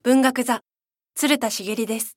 ボイスサンプルはこちら↓ 名前